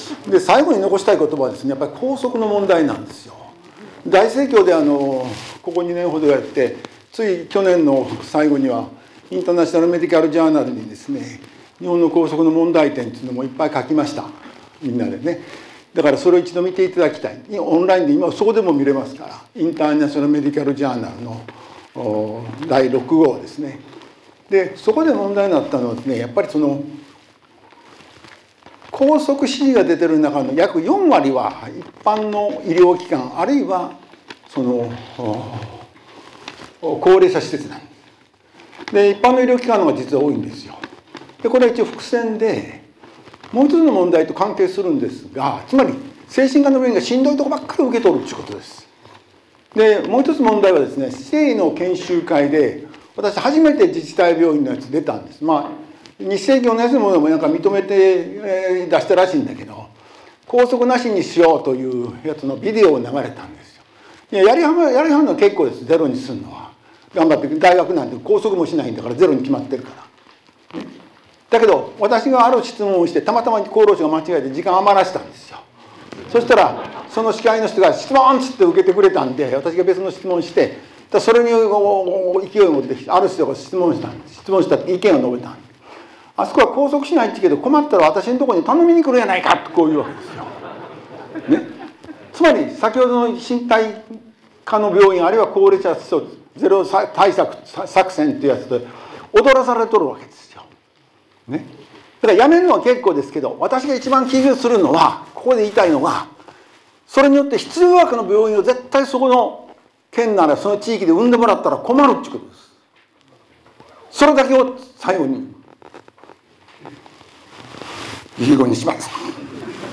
意見発表 （第26回日本精神科病院協会臨時社員総会2025年2月14日）